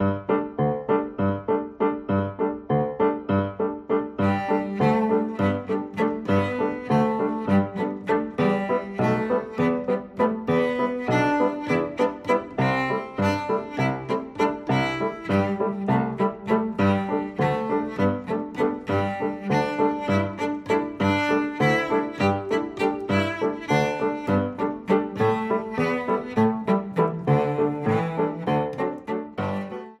Besetzung: Violoncello